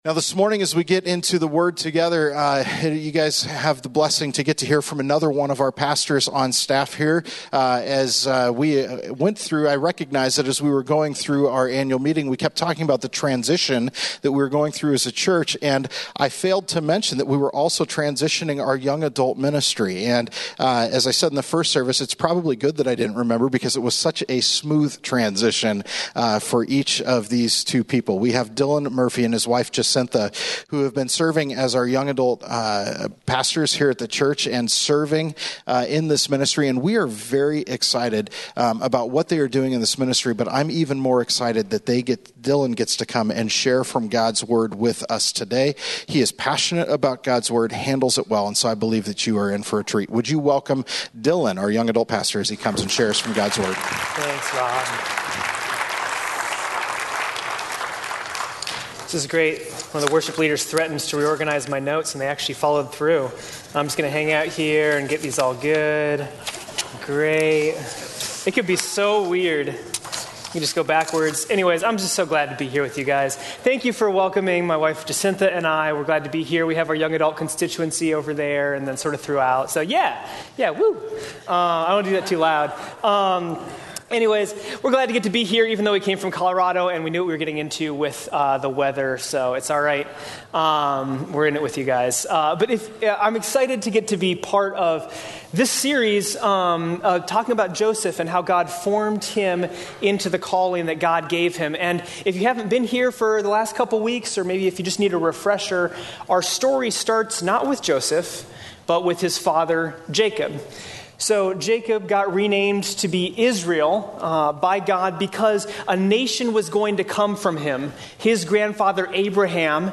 Westgate Chapel Sermons Becoming - Walking In The Dark Feb 17 2019 | 00:43:17 1x 00:00 / 00:43:17 Subscribe Share Apple Podcasts Overcast RSS Feed Share Link Embed